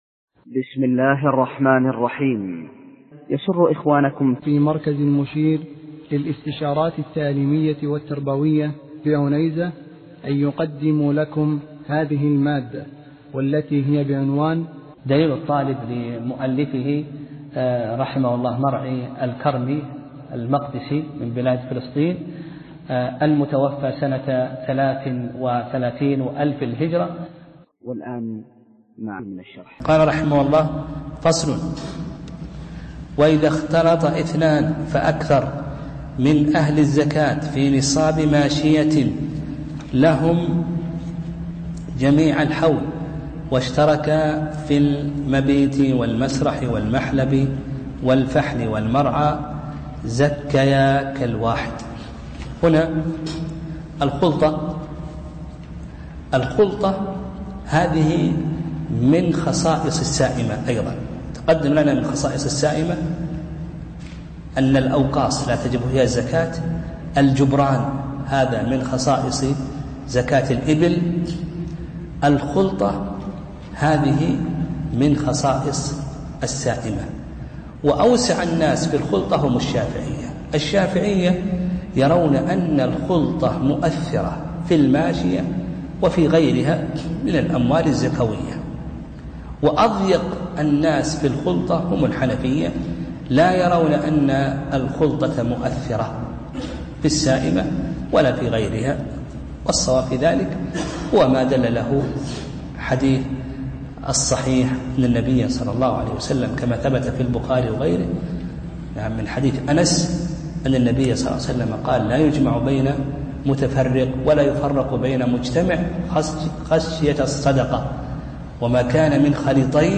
درس (3) : كتاب الزكاة: تتمة باب زكاة السائمة وباب زكاة الخارج من الأرض